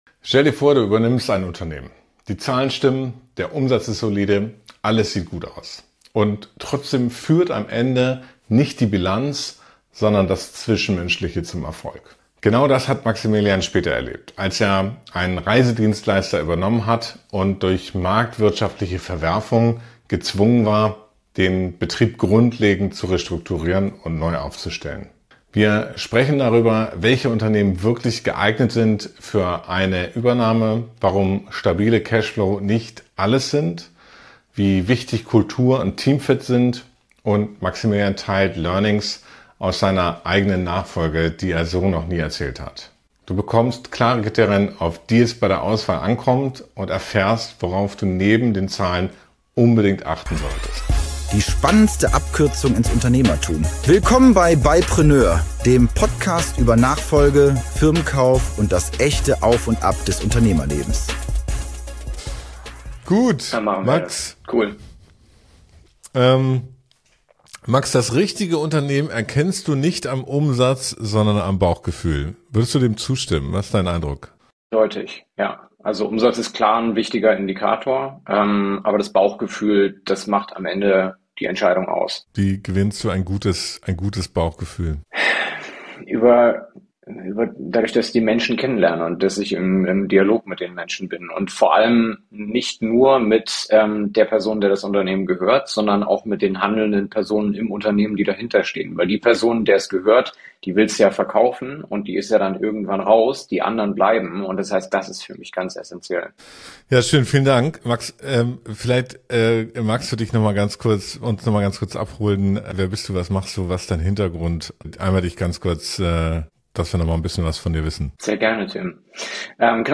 Nachfolge-Experte, Interim-Manager und Coach. Gemeinsam gehen sie der zentralen Frage nach: Welche Unternehmen eignen sich wirklich für eine Übernahme – und welche eher nicht?